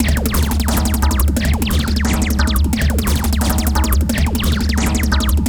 __SCRATX 2.wav